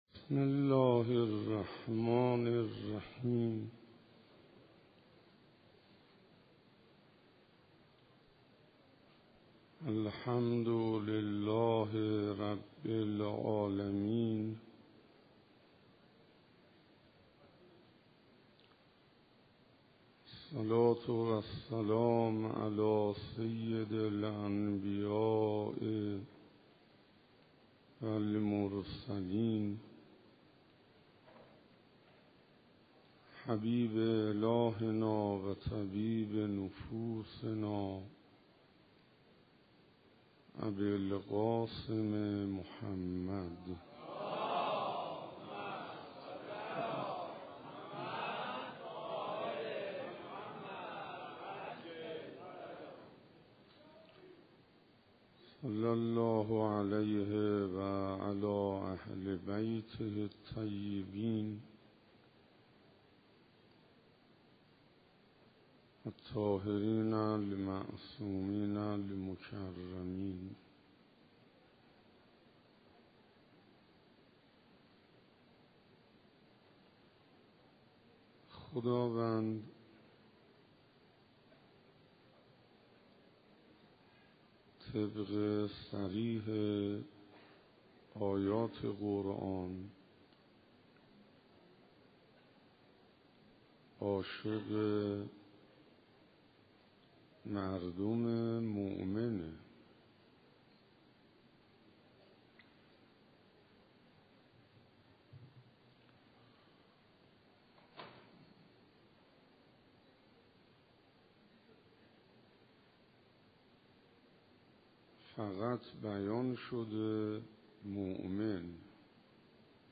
روز 18مسجد امیر علیه السلام رمضان 1396_معارف اسلامی